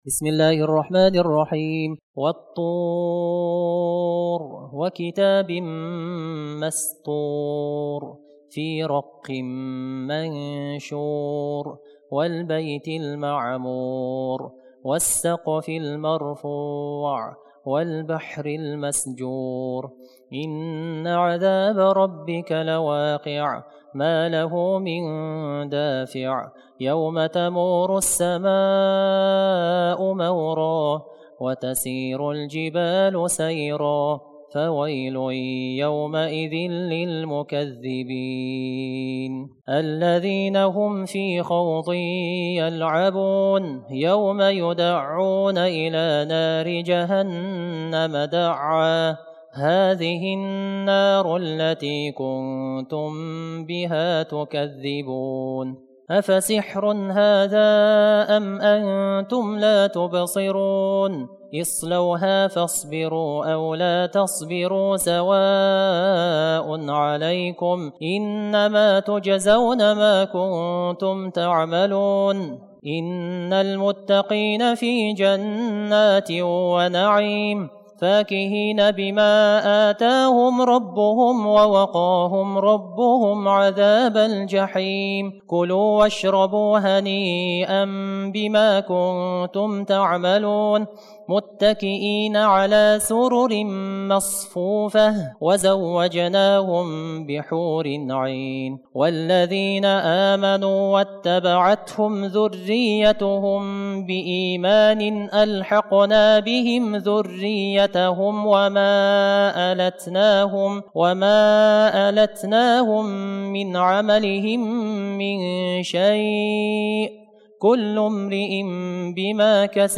Тарауих намазы 2019 - Хатым Құран